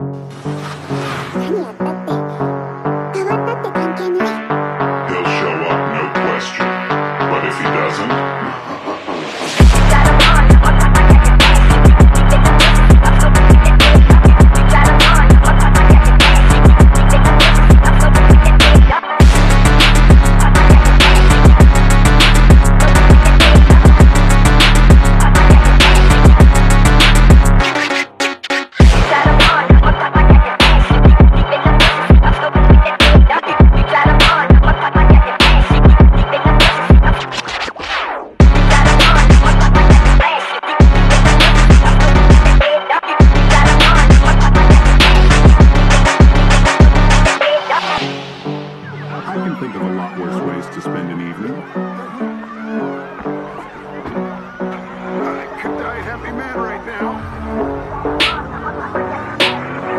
1,100 horsepower Turbo V12 Powered